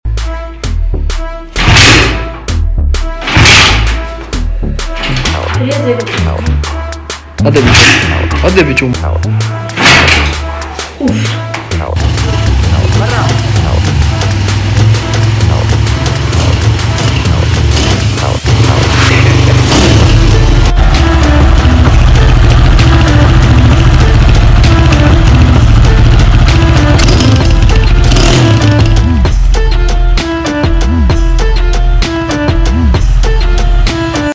Alter 250ccc sound check 🔥🔥 sound effects free download